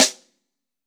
B.B SN 1.wav